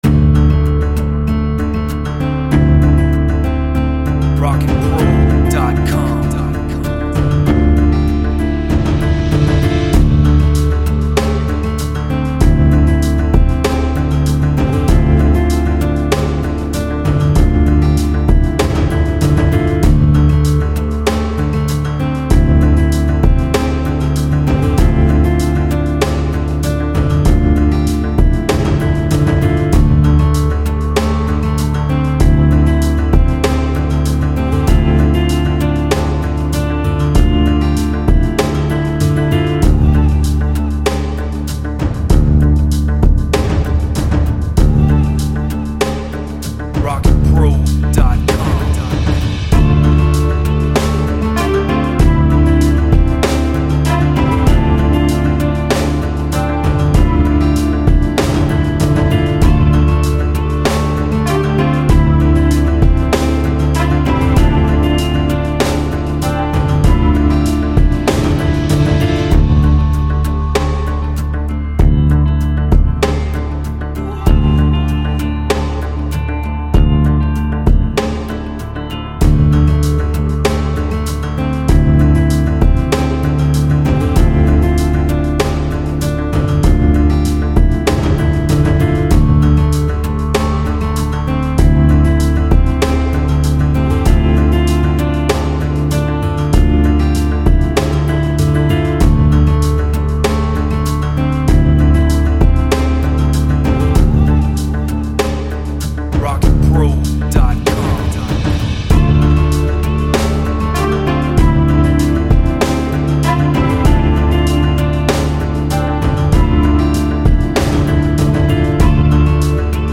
Dark